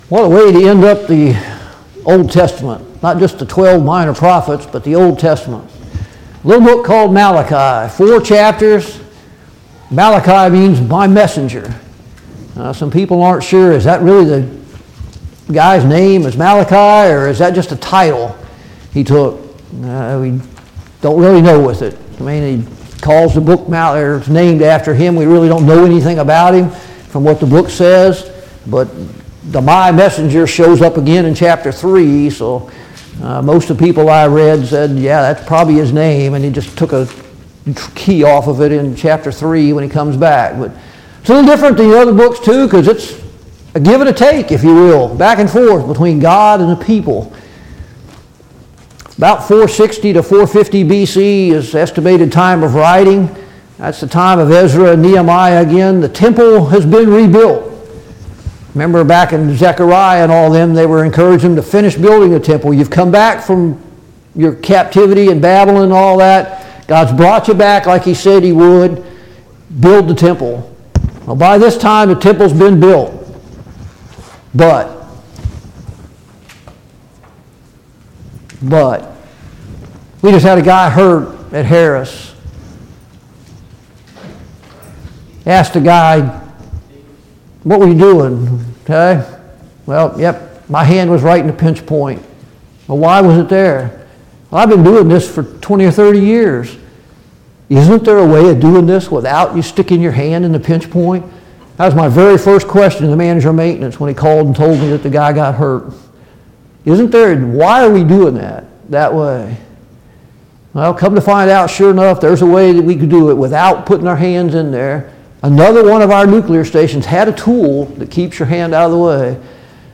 Malachi Service Type: Sunday Morning Bible Class « 26.